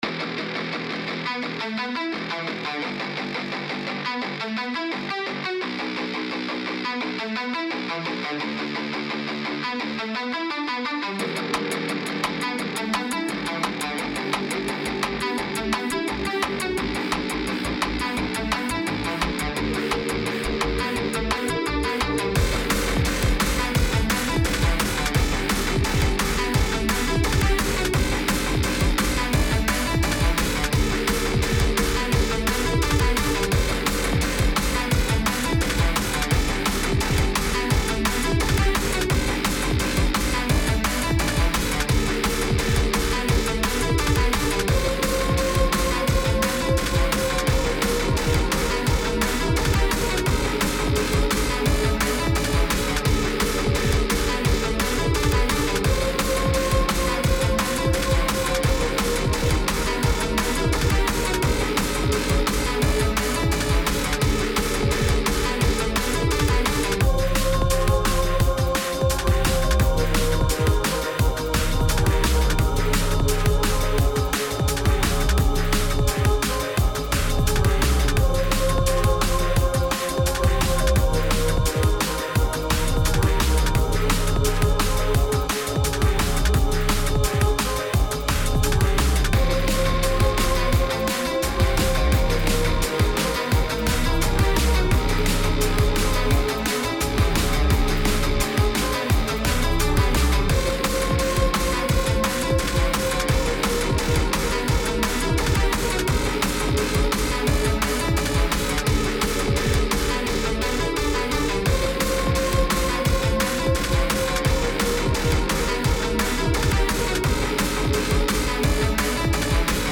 Expect power chords from guitars. This is the instrumental.
Tempo 172BPM (Vivace)
Genre Gritty Drum and Bass
Type Instrumental
Mood Conflicting (Aggressive/energetic)